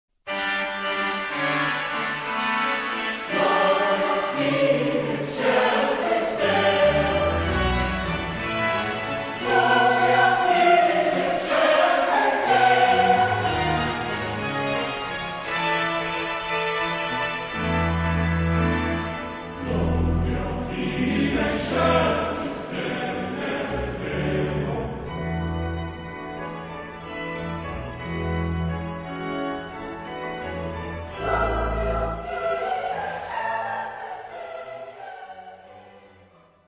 Tipo del material: Coro y organo
Género/Estilo/Forma: Sagrado ; Cantata
Carácter de la pieza : rítmico ; jubilado
Tipo de formación coral: SATB  (4 voces Coro mixto )
Instrumentación: Organo